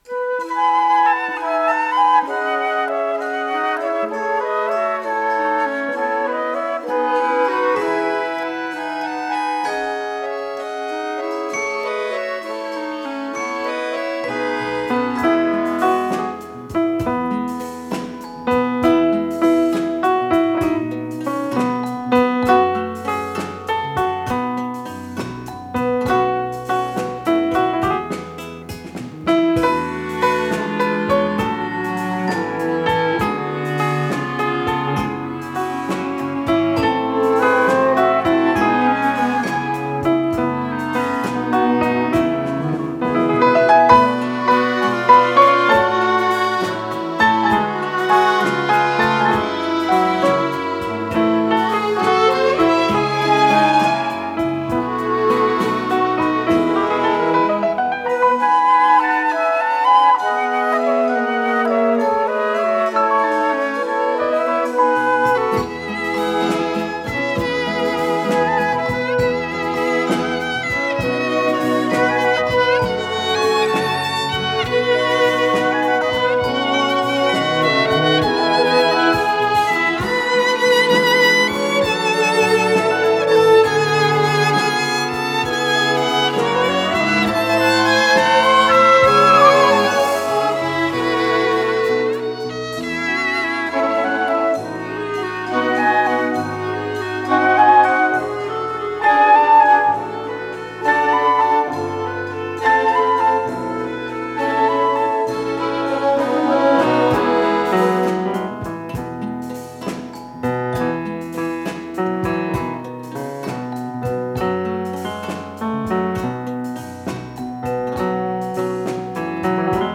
Подзаголовокзаставка
ВариантДубль моно